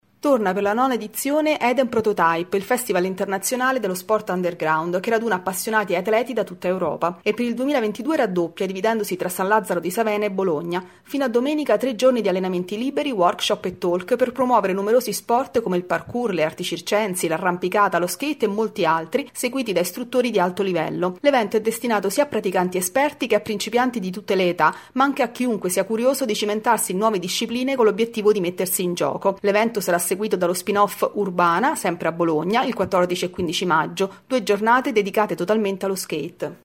Domani a Padova l’iniziativa pubblica per impedire lo sgombero di una palestra popolare. Il servizio